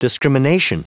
Prononciation du mot discrimination en anglais (fichier audio)
Prononciation du mot : discrimination